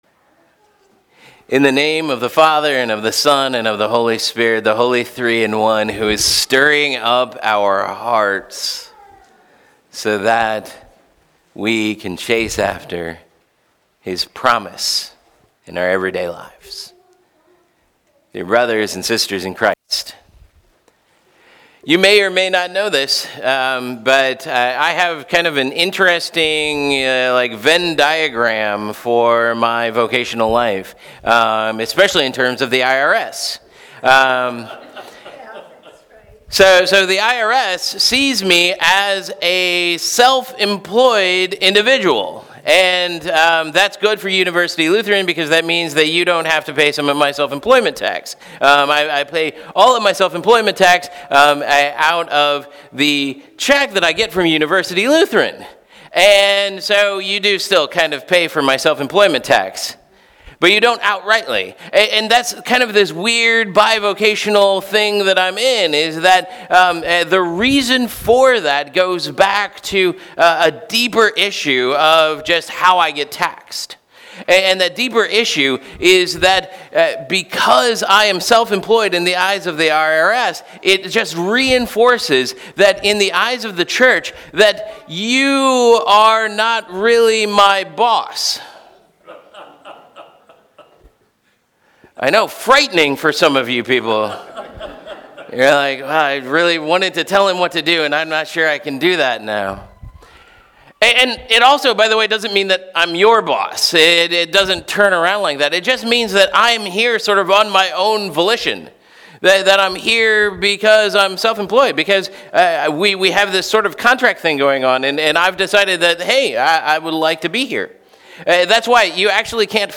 Sermon Scripture: Jeremiah 33:14–16